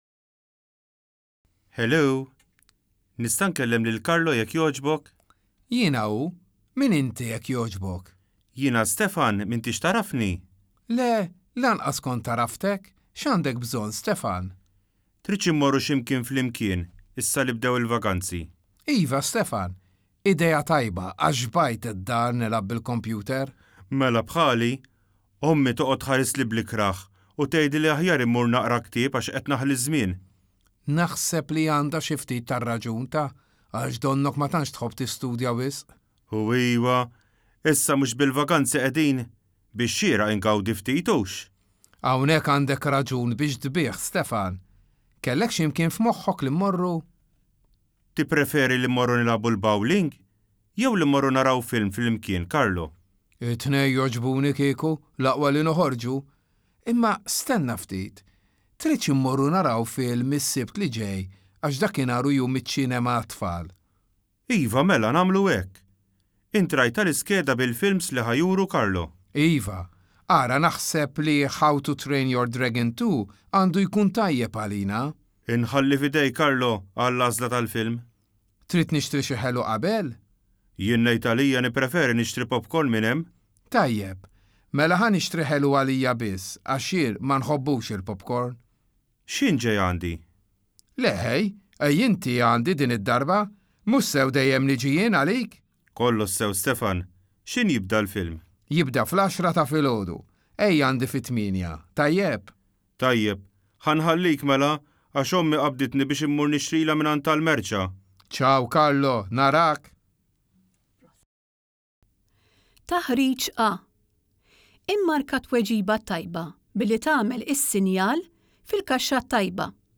Siltiet Irrekordjati għas-Smigħ, bit-Testi u l-Mistoqsijiet
Telefonata bejn Żewġt Iħbieb
telefonata bejn il-hbieb.wav